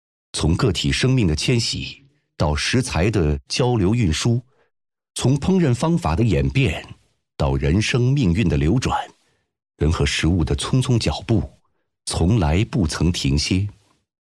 广告语音
[特型样音]
标签 怪物（怪兽，兽类） 卡通 写实 样音 旁白
【特型样音】是一种富有表现力的声音艺术，通过精准模仿具有鲜明个性的影视角色、经典人物及动物音色，展现声音的可塑性与戏剧张力。